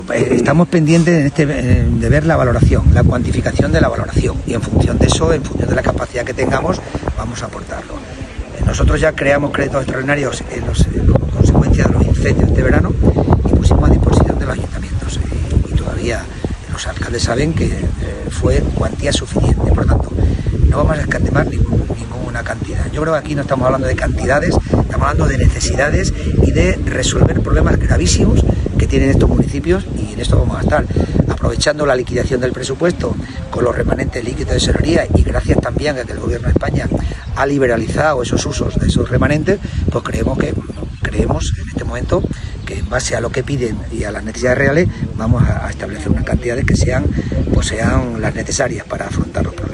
CORTES DE VOZ
Morales-vista-municipios-afectados-borrasca.mp3